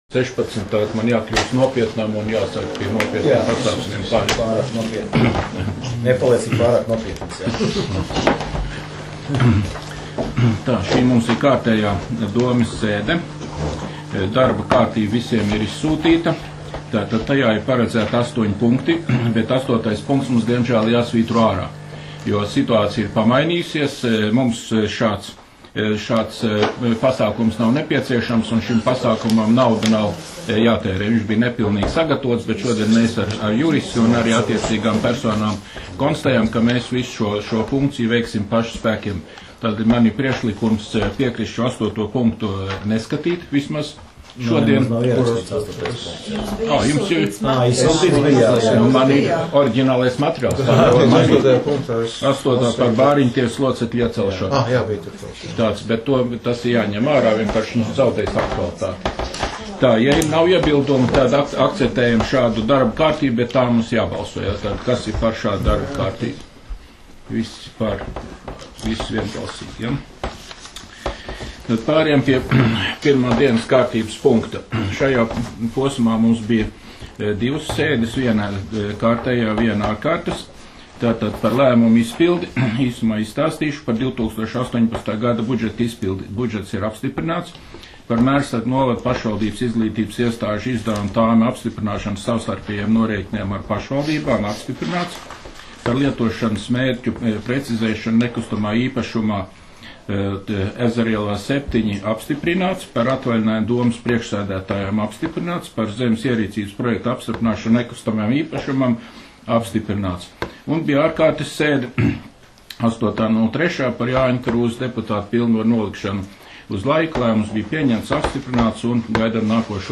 Mērsraga novada domes sēde 19.03.2019.